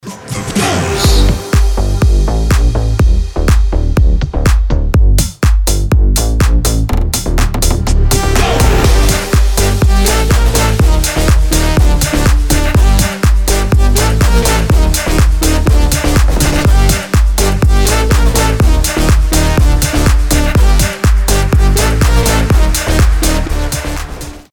• Качество: 320, Stereo
EDM
Club House
мощные басы
энергичные
electro house